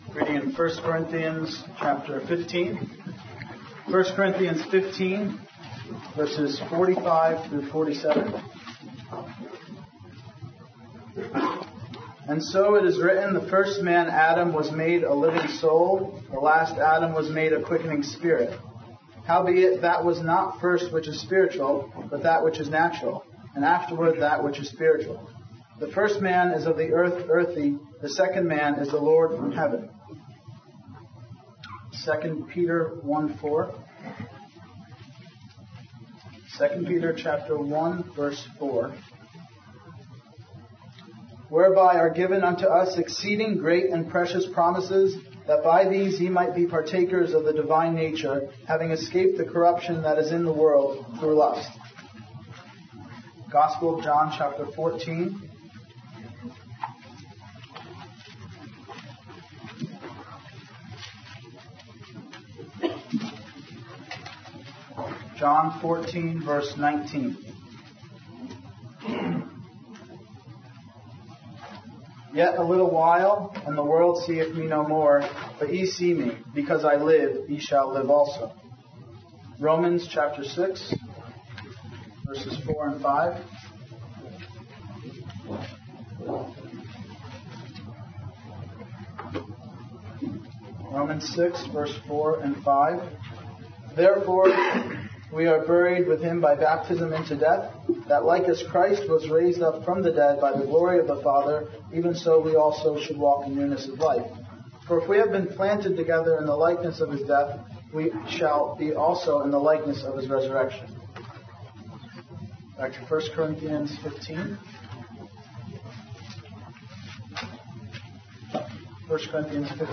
View our Christian Ministry downloadable mp3 recordings from lectures and conferences across the UK over the last 60 years.
2-Bible+Readings+on+the+Last+Adam+at+Clark+2007.mp3